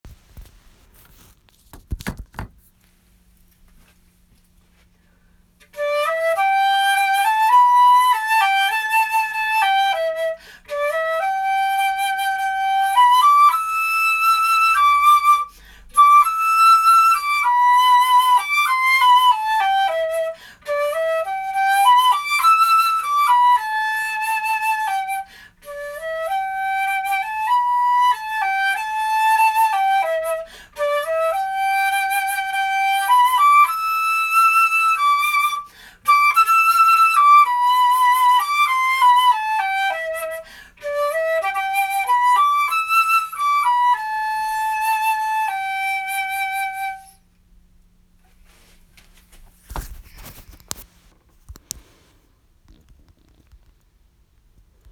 Audio – The fundraising flautist
One of my busking staples: Loch Lomond